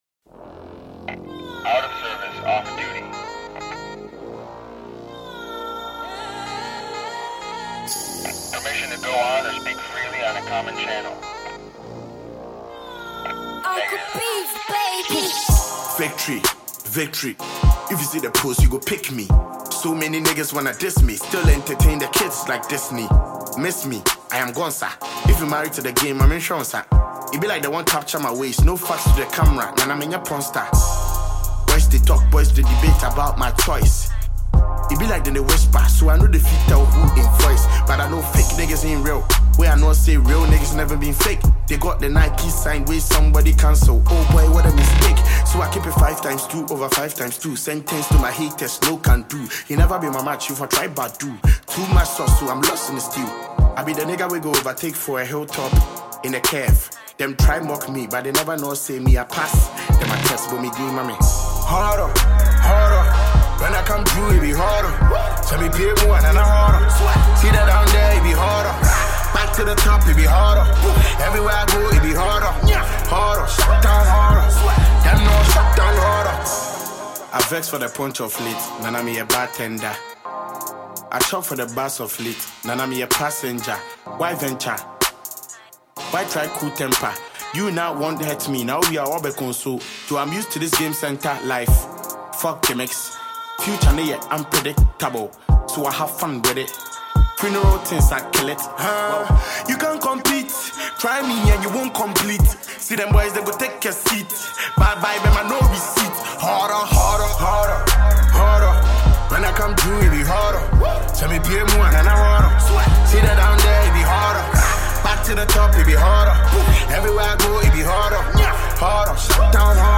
drill song